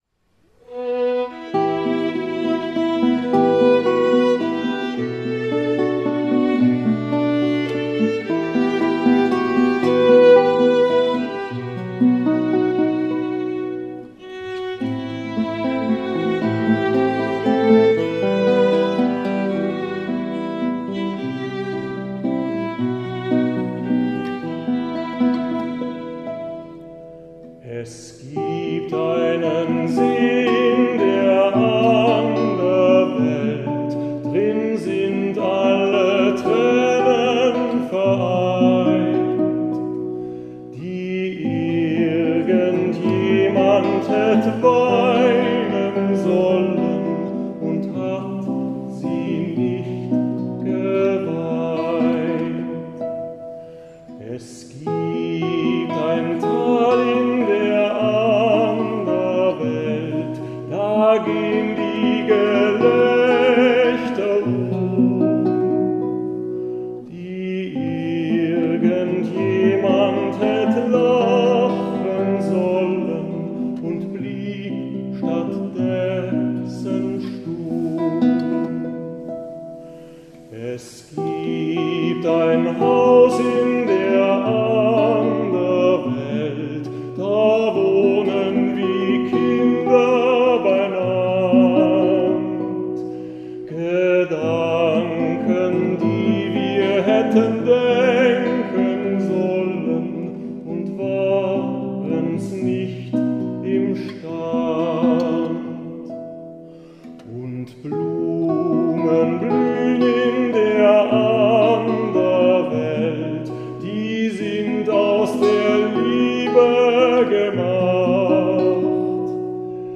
Live-Mitschnitt Kunstnacht 2024 & Probenschnipsel
Gesang & Violine
Gitarre
live Kunstnacht Augsburg 2024